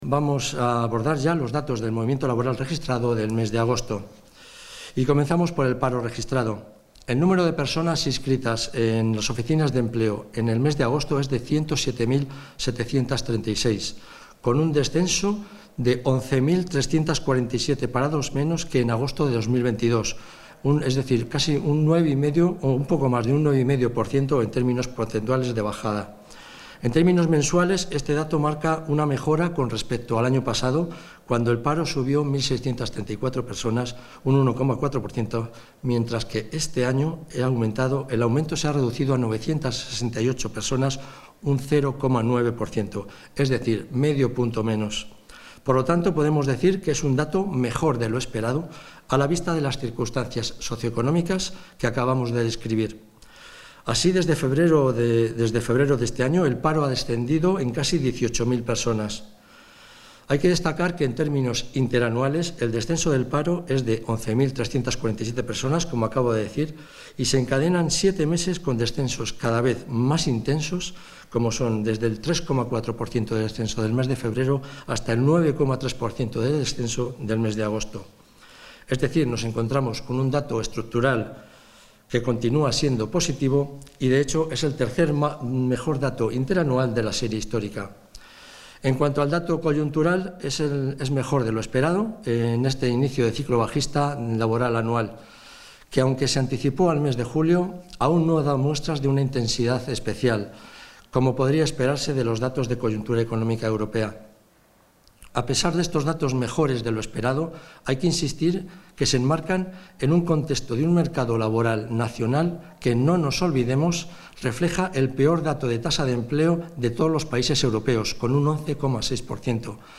El consejero de Industria, Comercio y Empleo, Mariano Veganzones, ha valorado hoy los datos de desempleo correspondientes al mes de agosto de 2023.